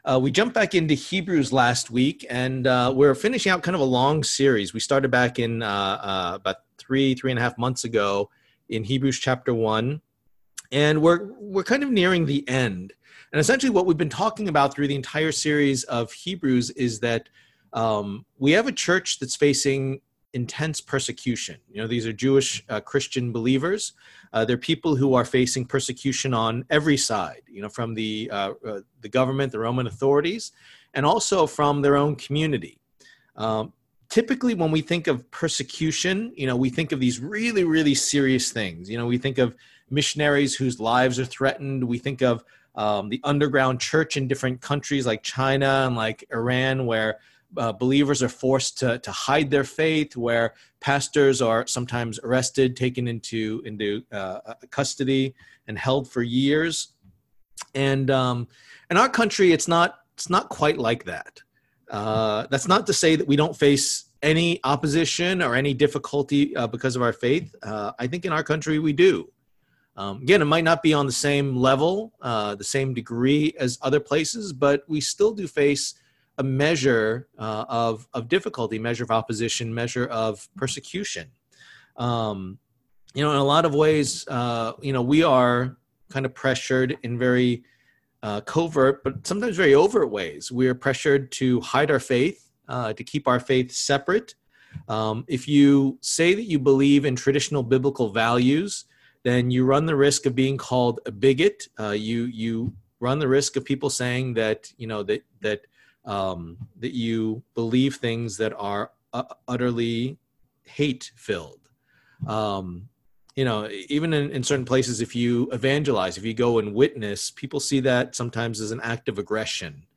Passage: Hebrews 12:3-17 Service Type: Lord's Day %todo_render% « Enduring the Race Why Should We Listen To Moses?